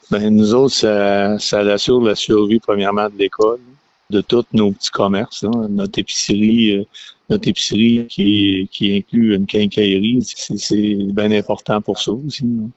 Le maire, Jean-Luc Boisclair, a rappelé l’importance de ce projet pour la municipalité de quelque 1 000 citoyens.